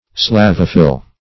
Search Result for " slavophil" : The Collaborative International Dictionary of English v.0.48: Slavophil \Slav"o*phil\, Slavophile \Slav"o*phile\, n. [Slavic + Gr.